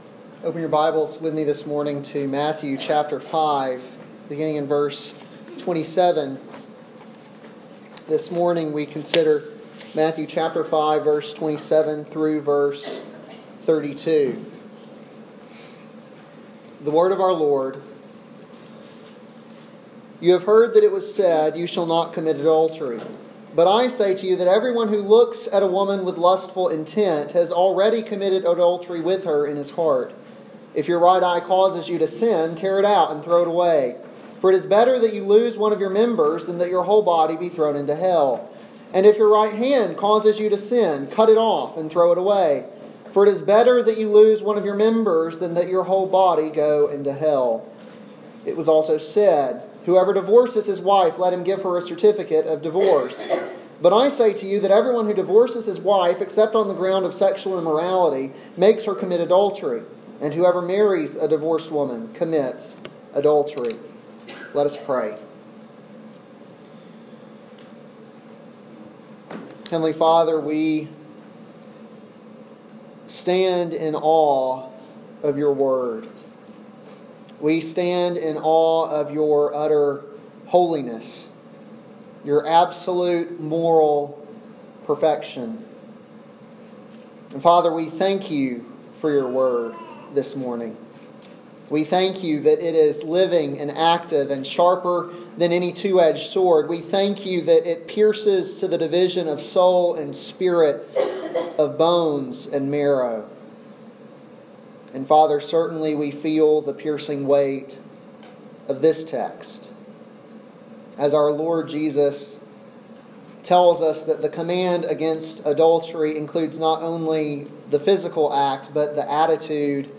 an expository sermon on Matthew 5:27-32